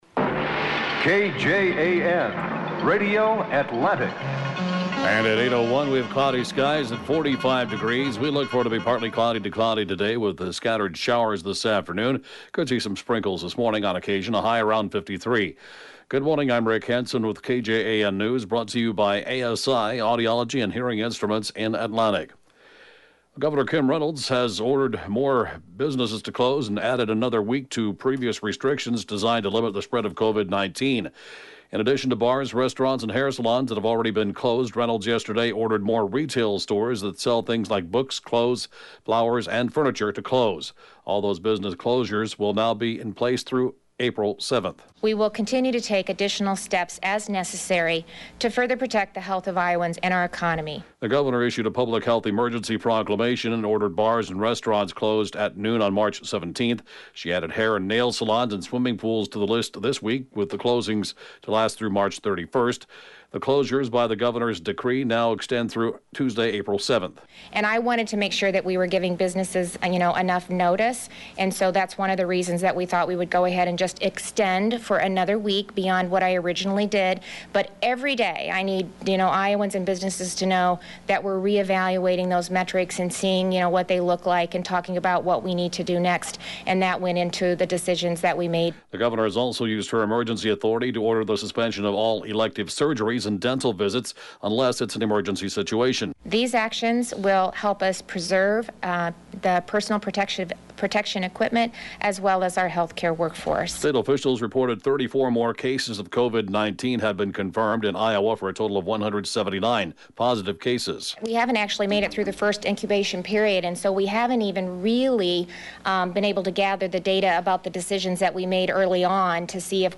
The area’s latest and/or top news stories at 7:06-a.m.